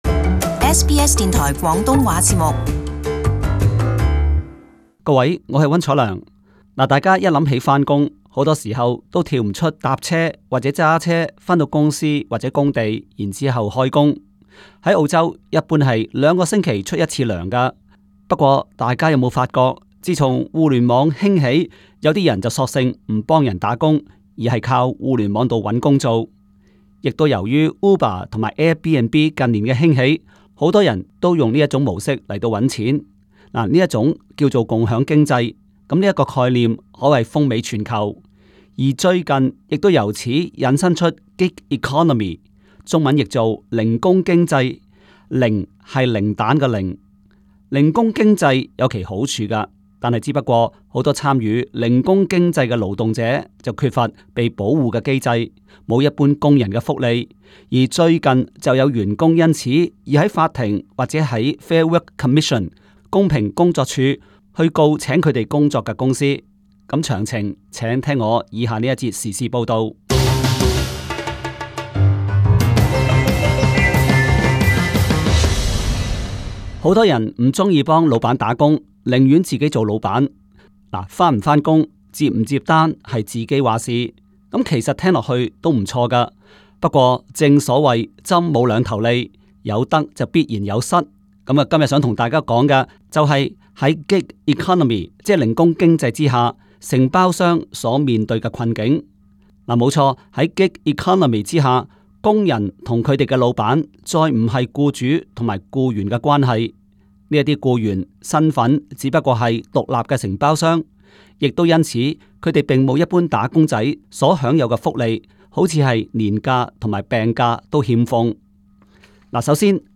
【時事報導】Gig Economy 零工經濟備受法律挑戰